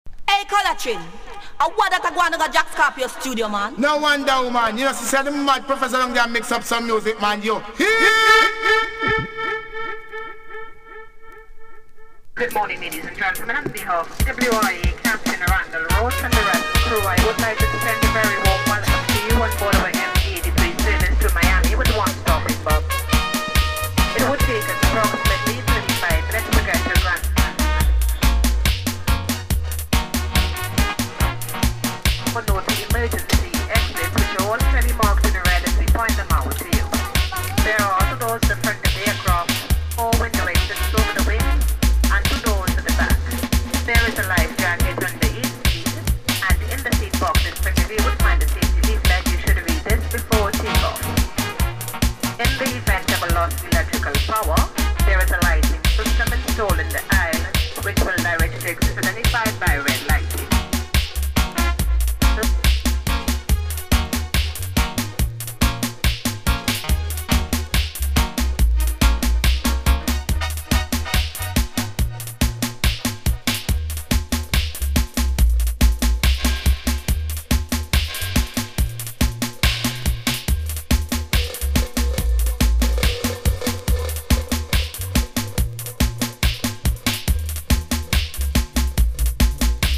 ソリッドなデジタル・ビートに心地良いダブ処理が最高です。
DUB / UK DUB / NEW ROOTS